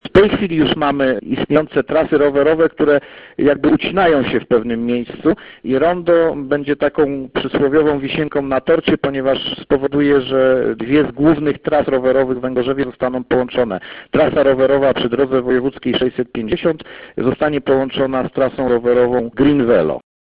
Mówi Krzysztof Piwowarczyk burmistrz Węgorzewa